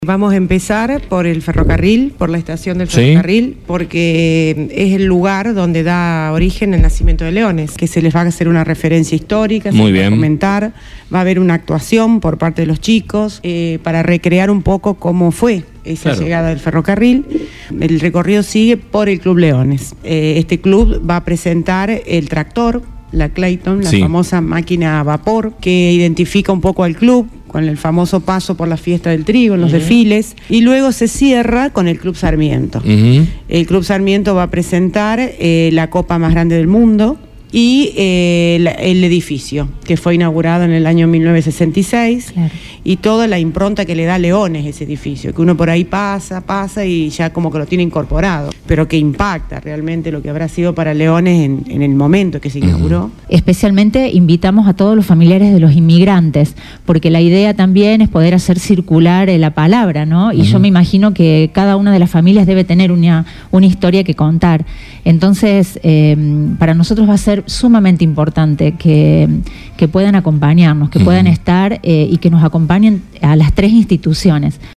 Escuchá un extracto del diálogo: